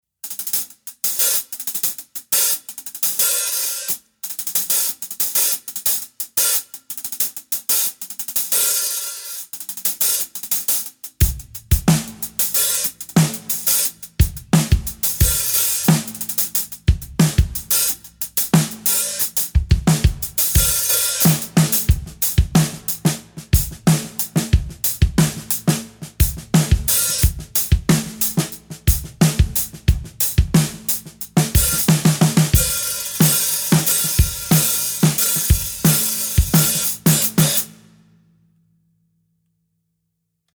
Sabian accomplished this by making subtle modifications in the manufacturing process to create conventional-sounding cymbals with more sharply defined characteristics.
Below are audio samples of the 2014 Cymbal Vote winners.
14″ AAX Freq Hats (click here)
Sabian-Freq-Hats.mp3